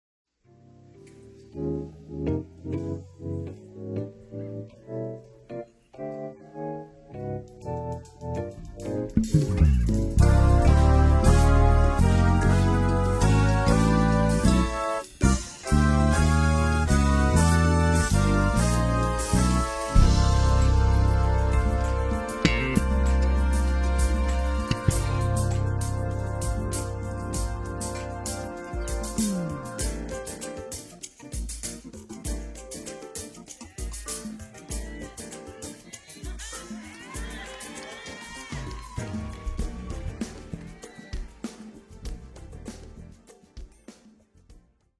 Live at the South Orange Performing Arts Center.